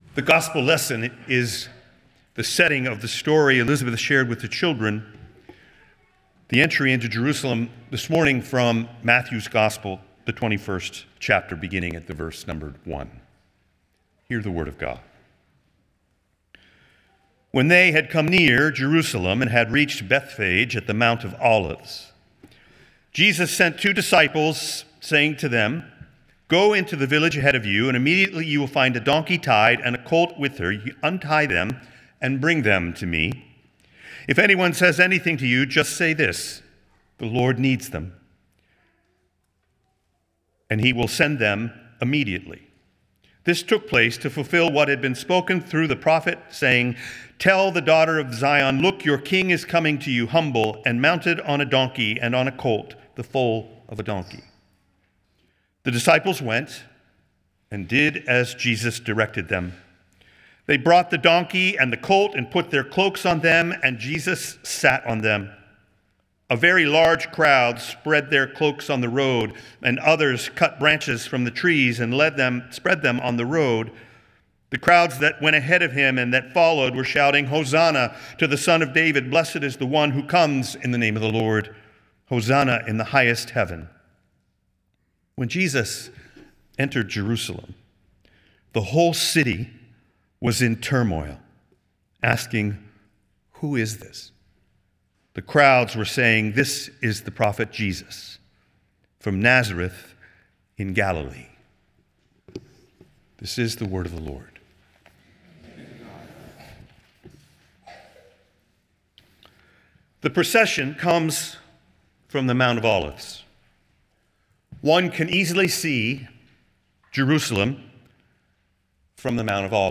Nassau Presbyterian Church Sermon